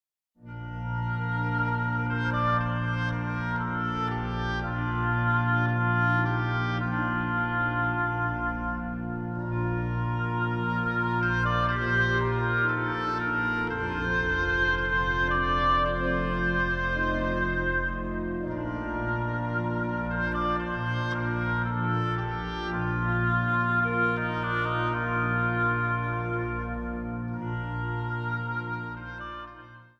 instrumentale